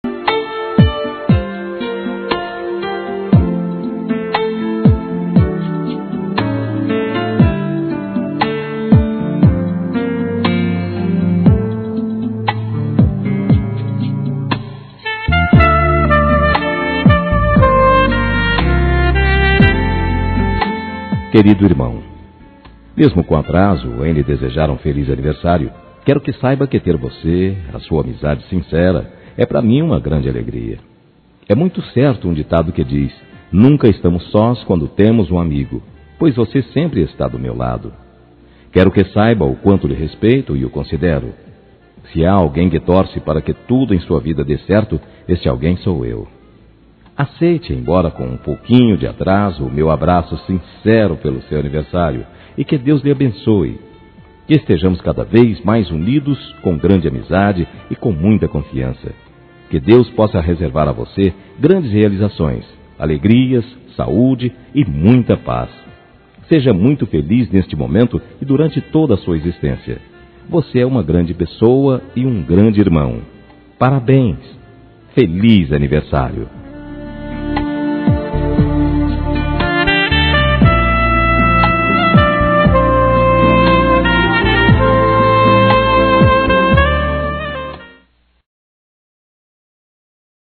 Aniversário Atrasado – Voz Masculina – Cód: 24922